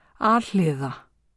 framburður
al-hliða